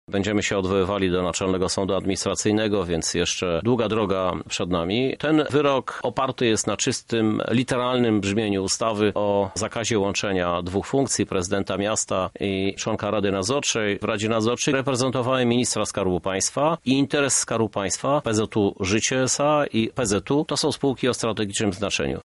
Żuk – mówi Prezydent Lublina Krzysztof Żuk.